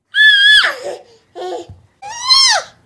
babyhappy.ogg